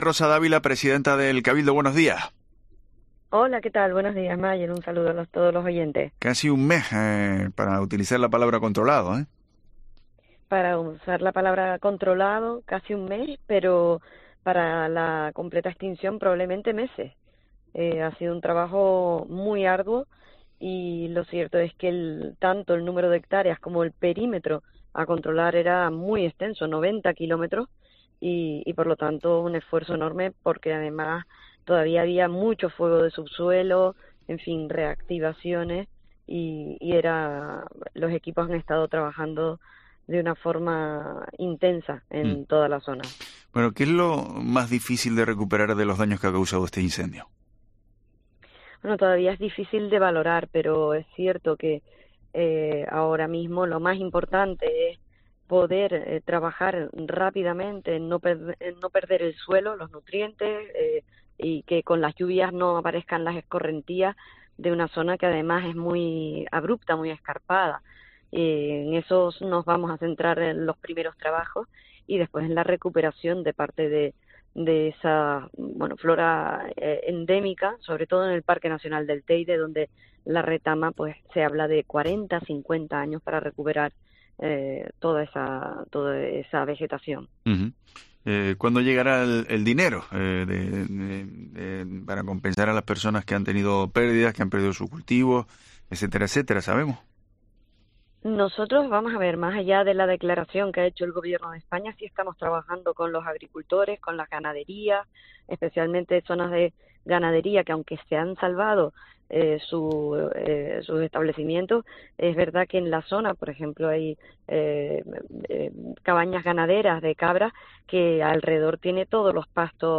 Rosa Dávila, presidenta del Cabildo de Tenerife, en Herrera en COPE Canarias